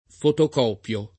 vai all'elenco alfabetico delle voci ingrandisci il carattere 100% rimpicciolisci il carattere stampa invia tramite posta elettronica codividi su Facebook fotocopiare v.; fotocopio [ fotok 0 p L o ], -pi (raro, alla lat., -pii )